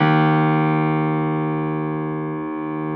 53h-pno03-D0.aif